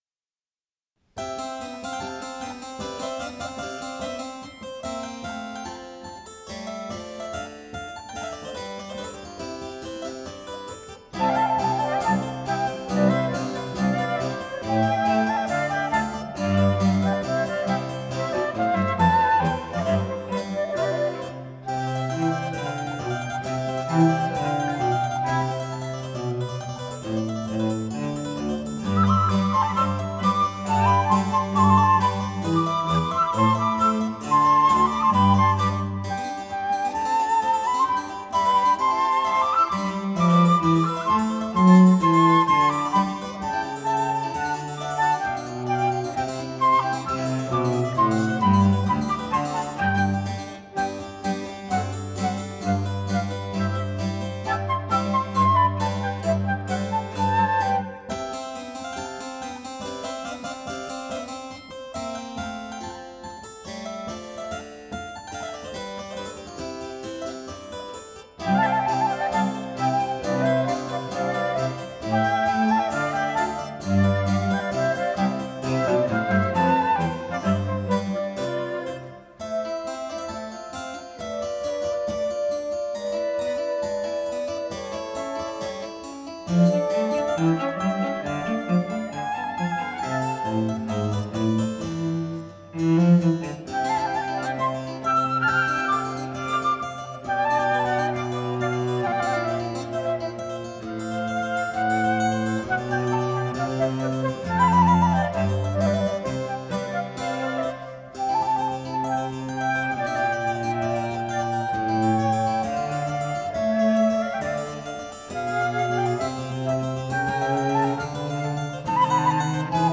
Incamto ensemble | Live recording | InCamTo
Sonata in Do maggiore, per due flauti e cembalo obbligato
Chiesa di Santa Chiara, Torino